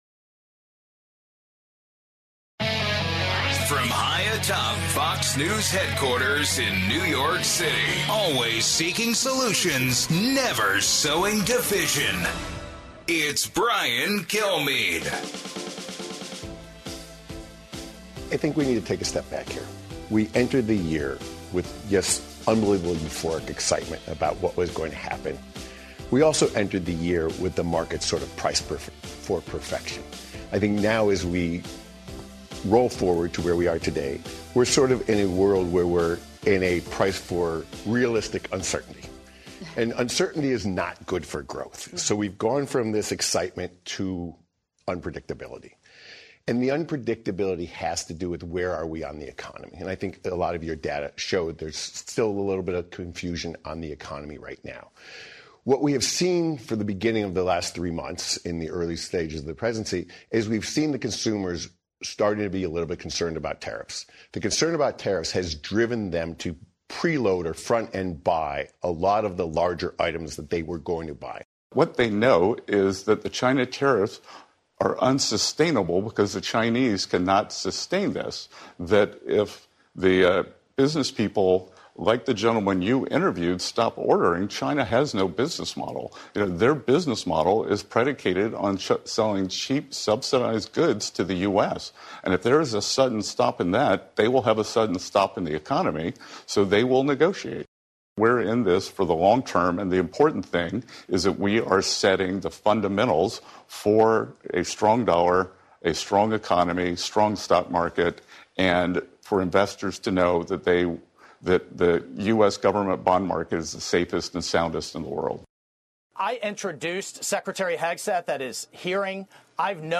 LIVE From the White House: The first 100 days